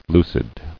[lu·cid]